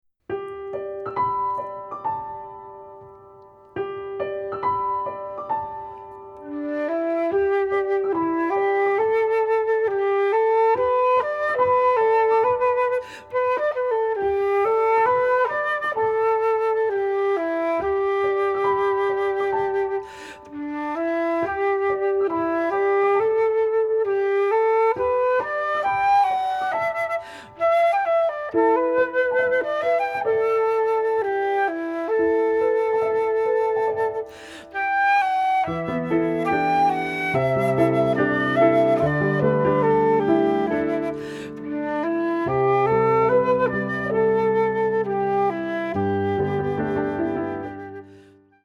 Pour flûte et piano